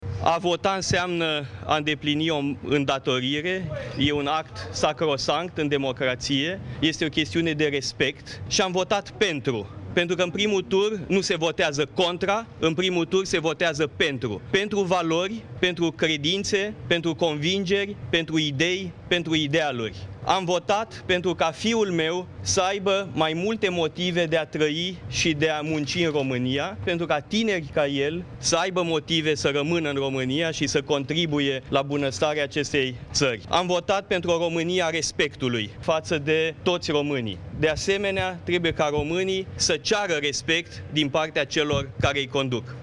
Candidatul PMP, Theodor Paleologu, a votat la Liceul Spiru Haret din București.